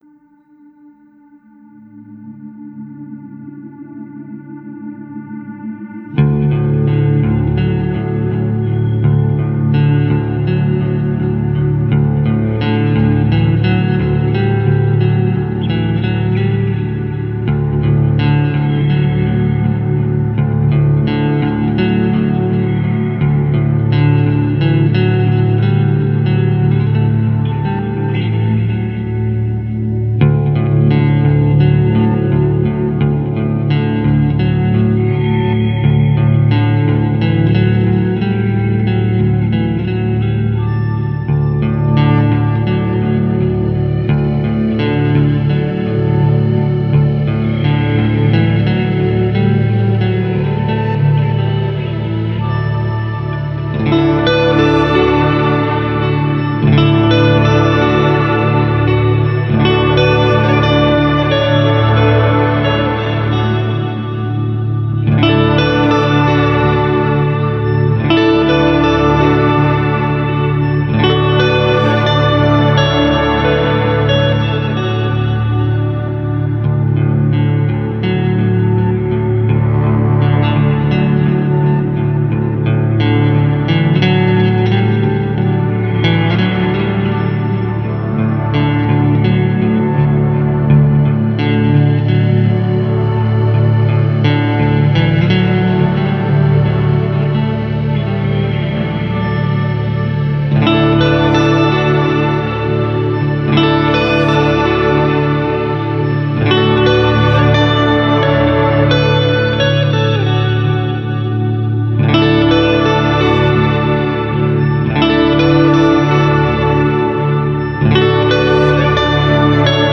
Warm beautiful atmospheric guitar scape.